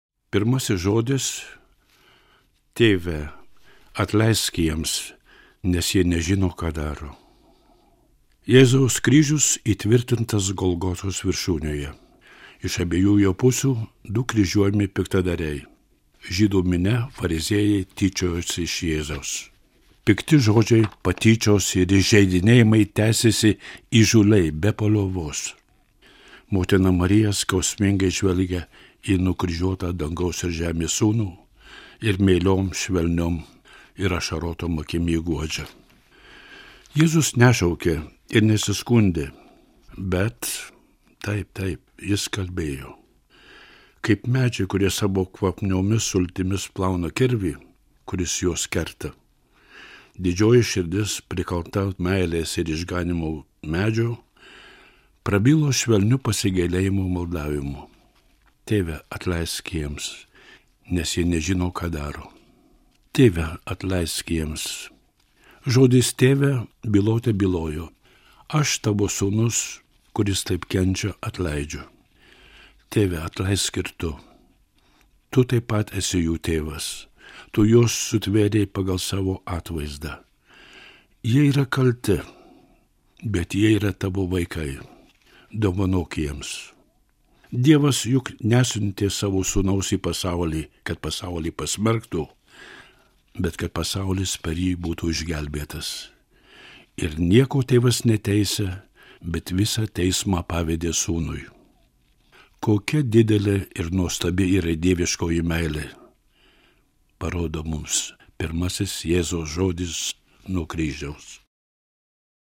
skaitovas / narrator